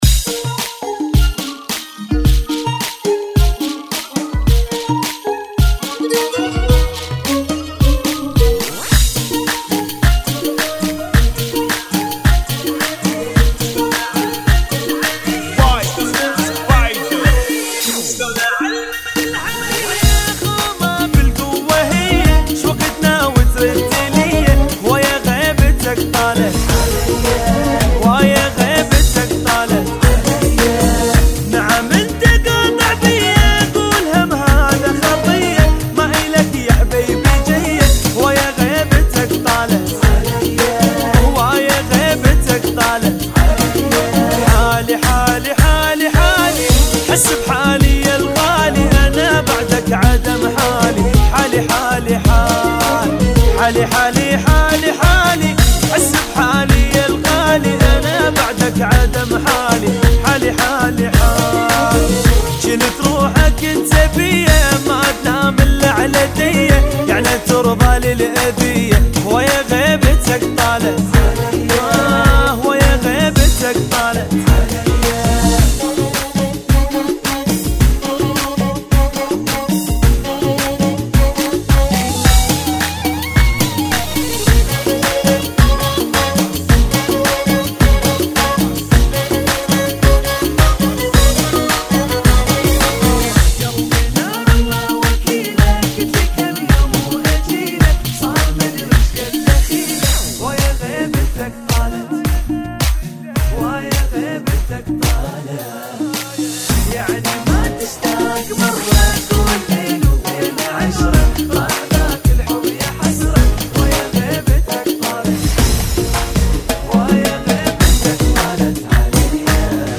Funky [ 108 Bpm ]